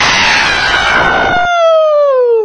描述：一个女人在尖叫。
Tag: 666moviescreams 尖叫 女人